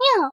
nya_2.ogg